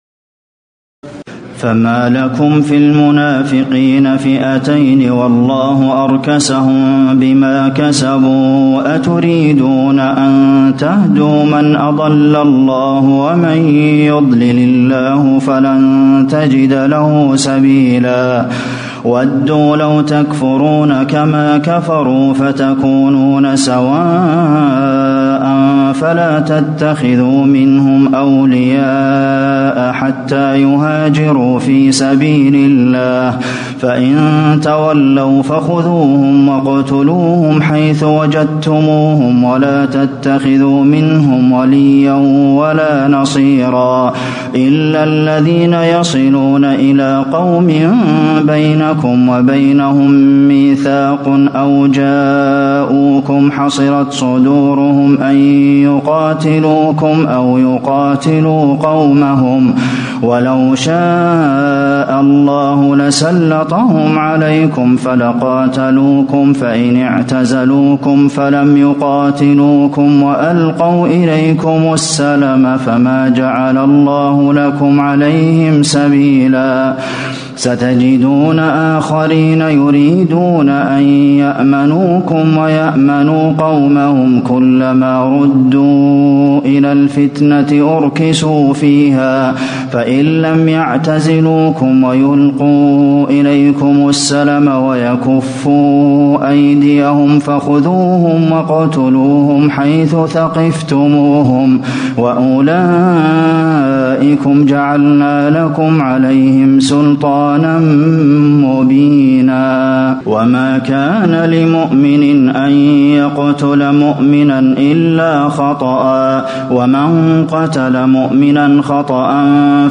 تراويح الليلة الخامسة رمضان 1437هـ من سورة النساء (88-147) Taraweeh 5 st night Ramadan 1437H from Surah An-Nisaa > تراويح الحرم النبوي عام 1437 🕌 > التراويح - تلاوات الحرمين